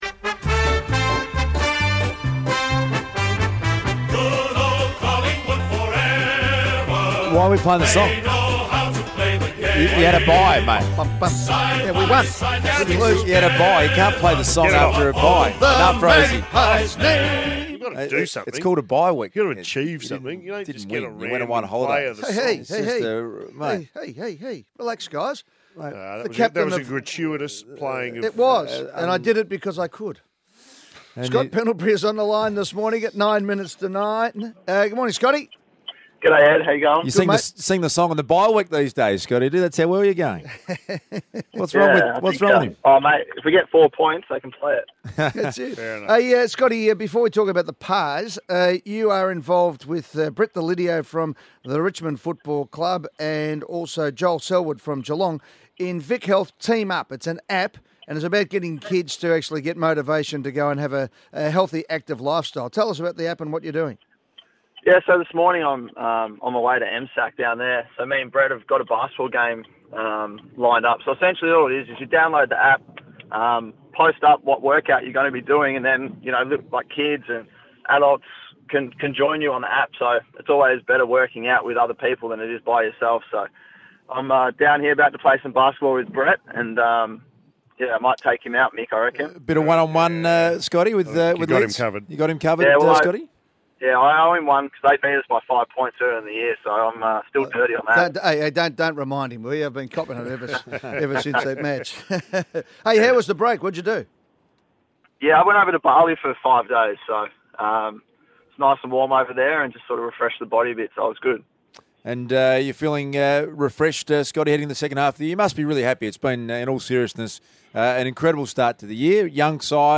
Tune in as captain Scott Pendlebury joins the Hot Breakfast team on Melbourne's Triple M on